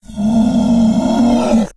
growl-2.ogg